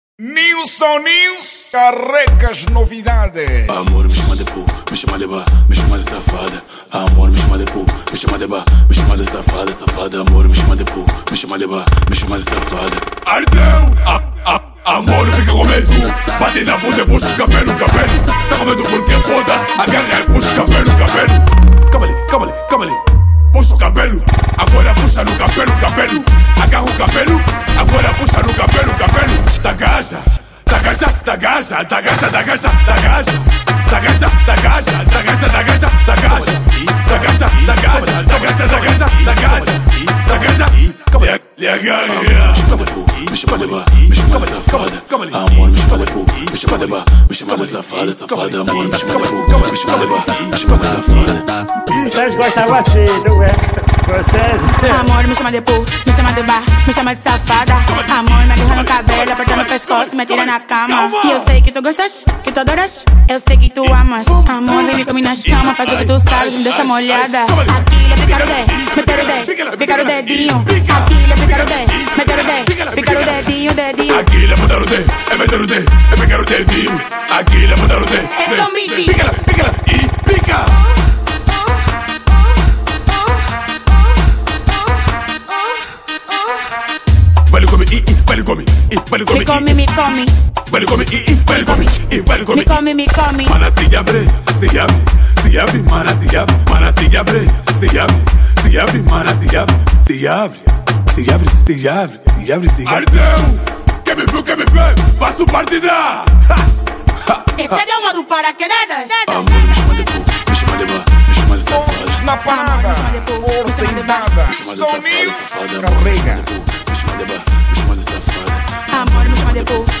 | Afro Music